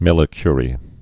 (mĭlĭ-kyrē, -ky-rē)